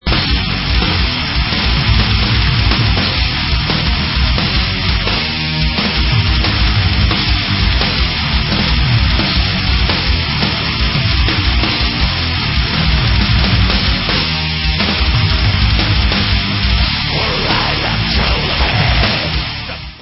sledovat novinky v oddělení Black Metal